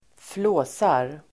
Uttal: [²fl'å:sar]